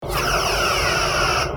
OtherPhaser1.wav